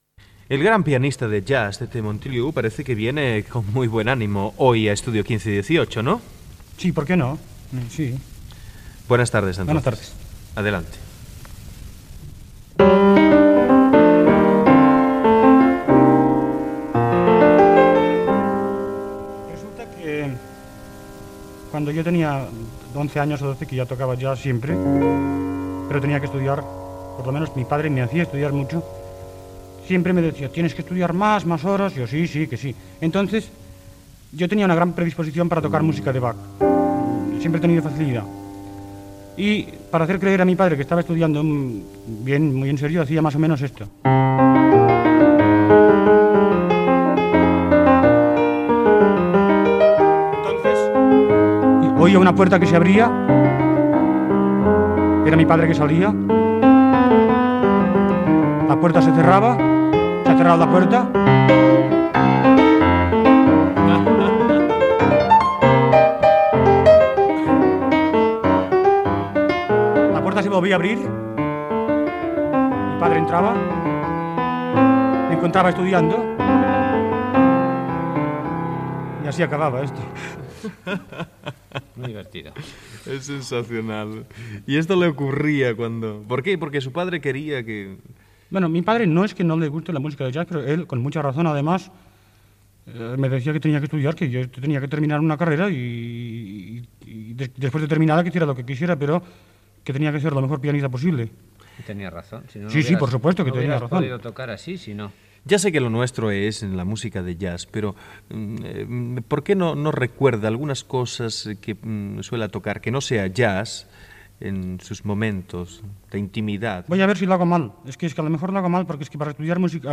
Entrevista al pianista Tete Montoliu qui també fa algunes interpretacions al piano